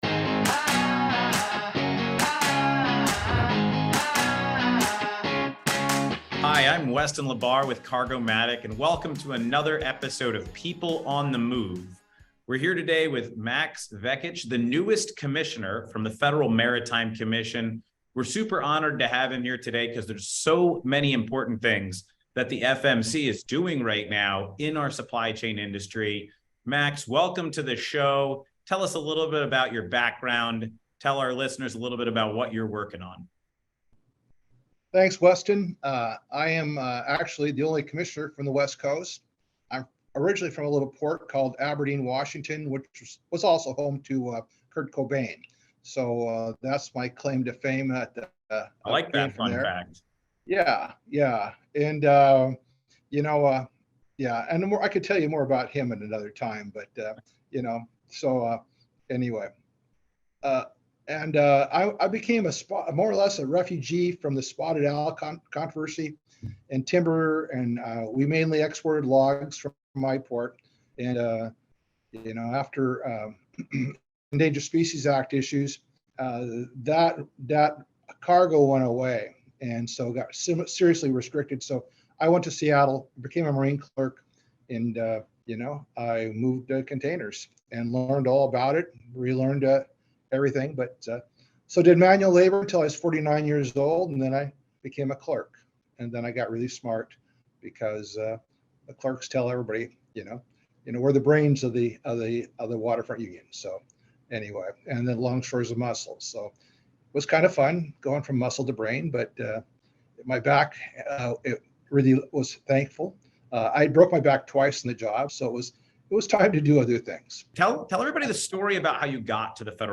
interviews Max Vekich, the newest commissioner from the Federal Maritime Commission (FMC).